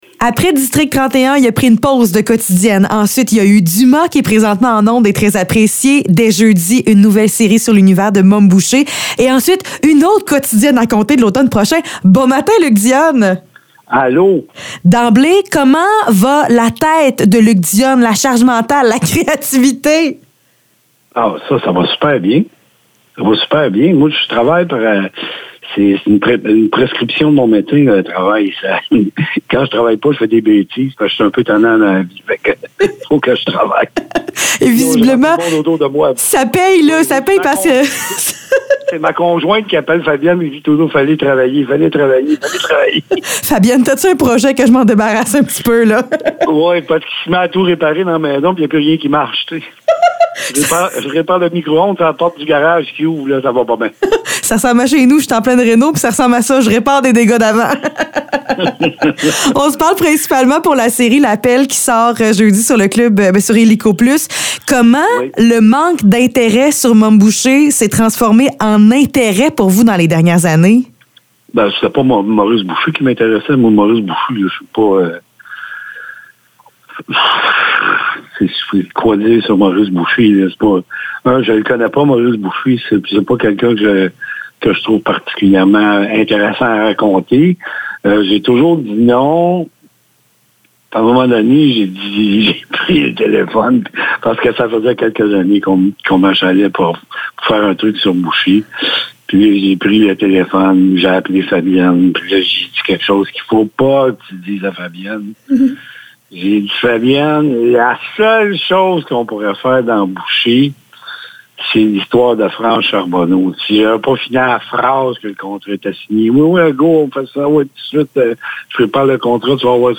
Entrevue avec Luc Dionne